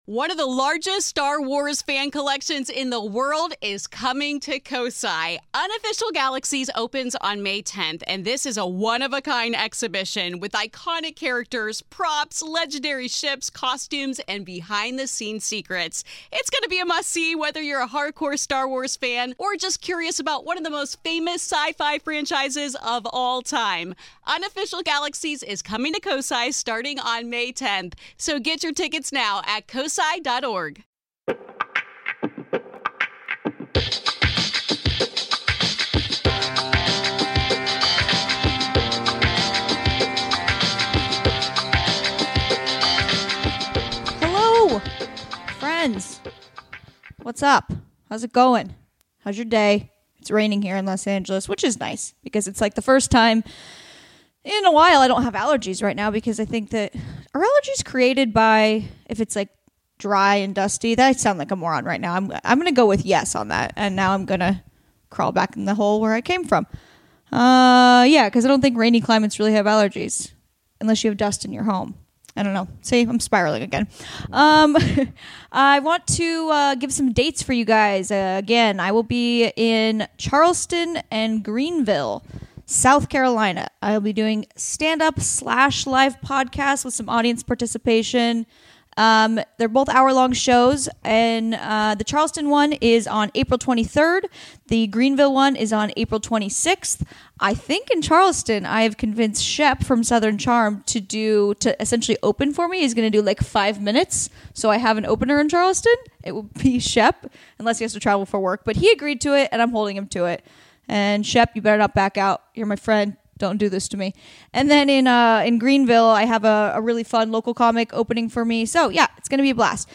Education, Comedy, Comedy Interviews, Self-improvement